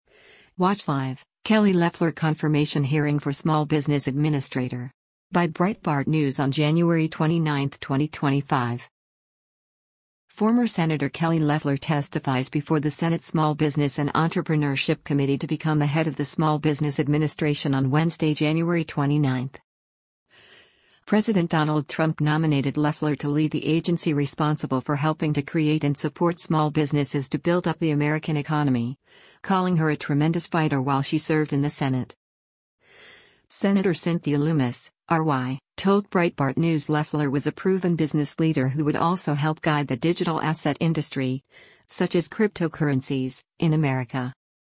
Former Senator Kelly Loeffler testifies before the Senate Small Business and Entrepreneurship committee to become the head of the Small Business Administration on Wednesday, January 29.